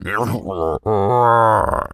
hippo_groan_03.wav